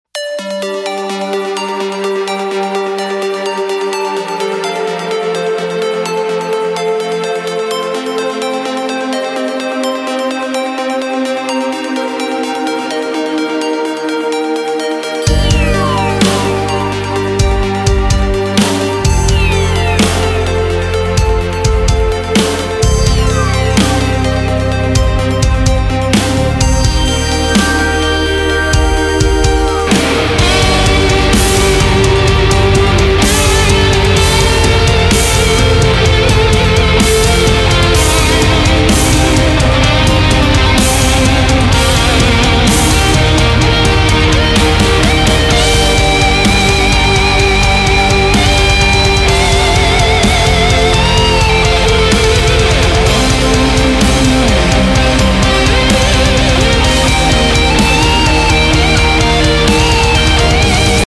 • Качество: 256, Stereo
гитара
без слов
рок
Trance metal